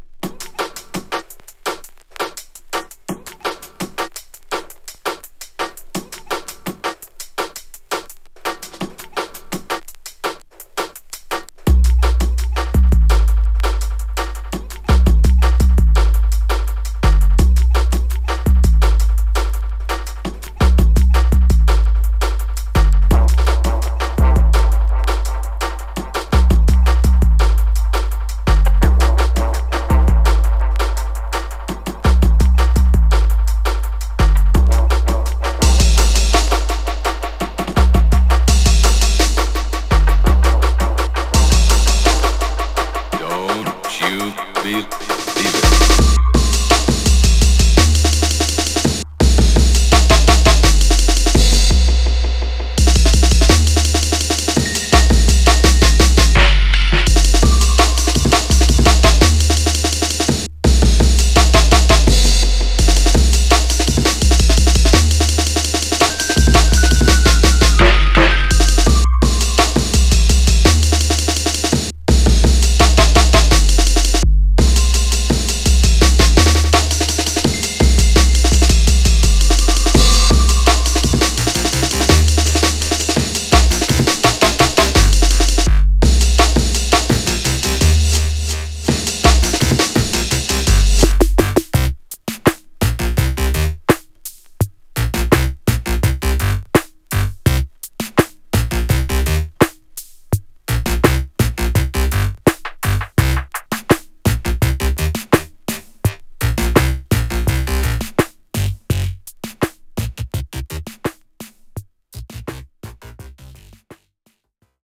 > BASS / DUB STEP / DRUM N' BASS